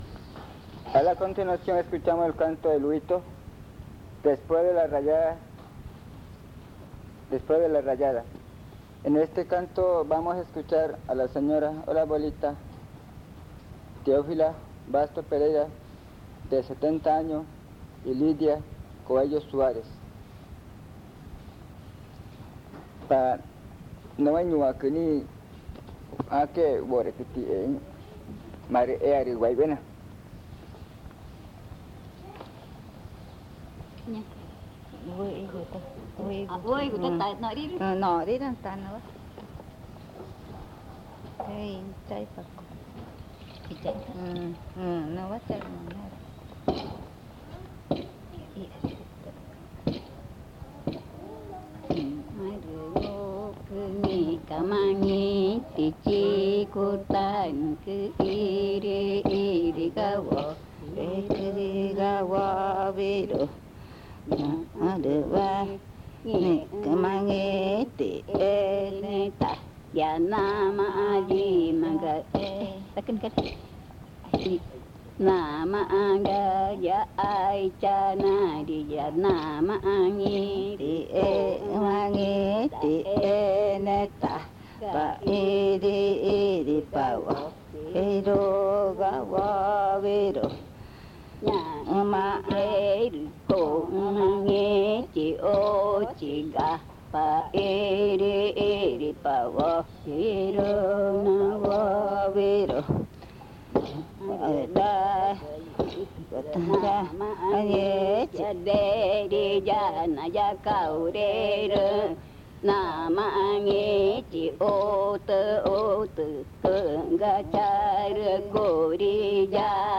Pozo Redondo, Amazonas (Colombia)
The elders perform a song in Magütá addressed to the young woman, offering guidance while she is being painted with ground huito bran, preparing her to rejoin the world after her period of confinement (enchanted). The song describes the application of the huito paint and the bathing ritual that follows, providing advice and warning her that disobedience may lead to criticism and gossip from people outside.